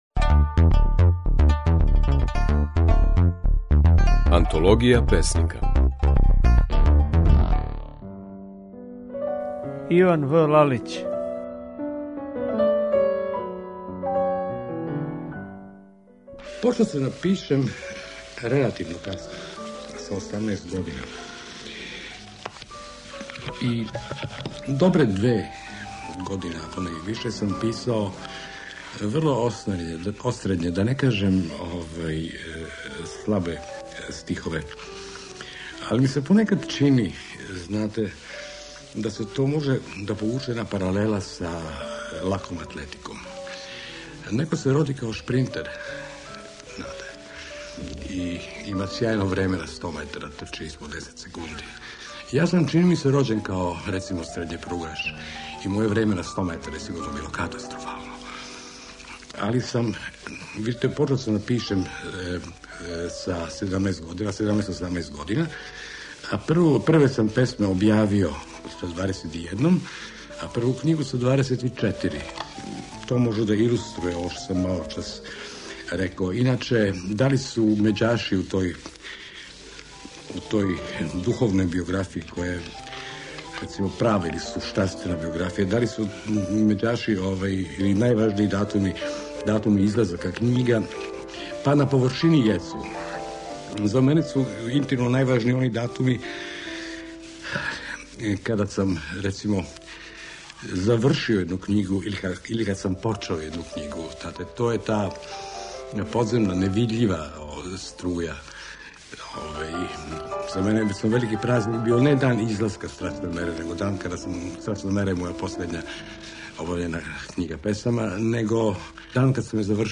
Како је своје стихове говорио Иван В. Лалић